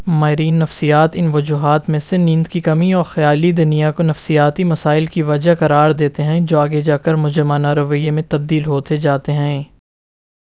deepfake_detection_dataset_urdu / Spoofed_TTS /Speaker_08 /101.wav